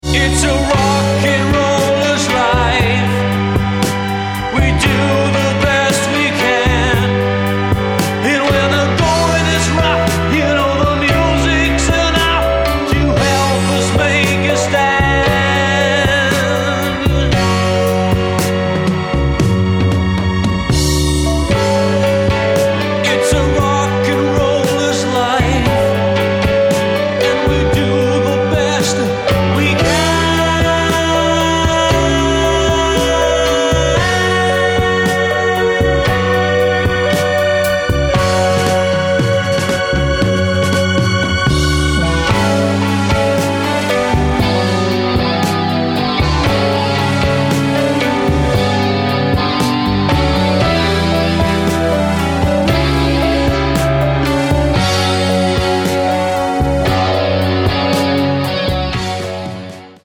Lead Vocal, Keyboards & Percussion.
Guitars & Vocal.